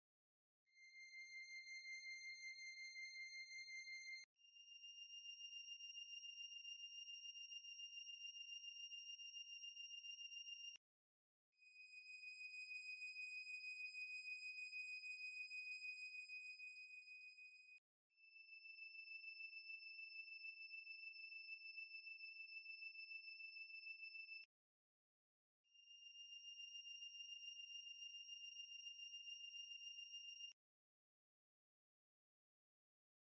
Sand vibrating to SOUND frequencies, sound effects free download